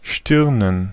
Ääntäminen
Ääntäminen Tuntematon aksentti: IPA: /ˈʃtɪʁnən/ Haettu sana löytyi näillä lähdekielillä: saksa Käännöksiä ei löytynyt valitulle kohdekielelle. Stirnen on sanan Stirn monikko.